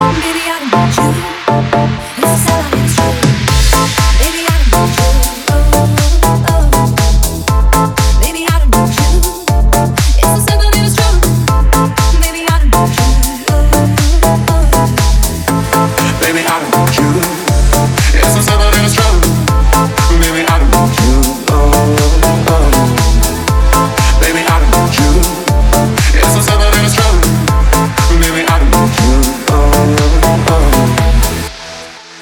• Качество: 320, Stereo
мужской голос
ритмичные
Electronic
дуэт
красивый женский голос
Стиль: house